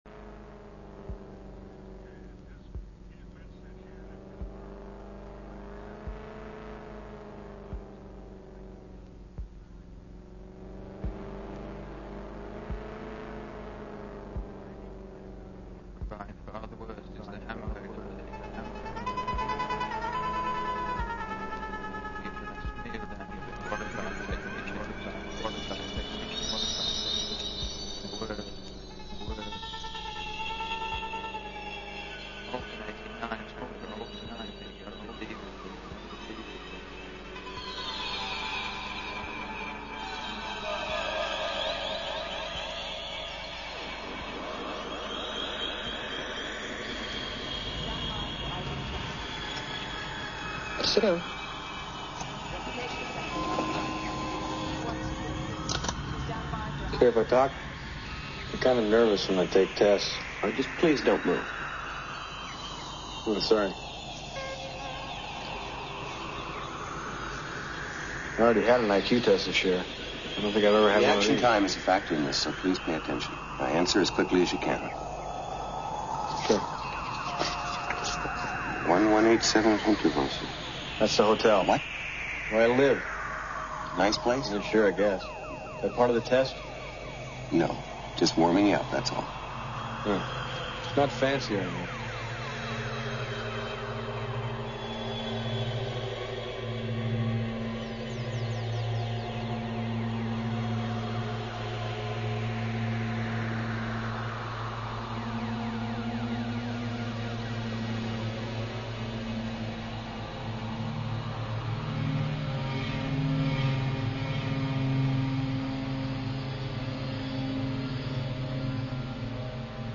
Style : electro - experimental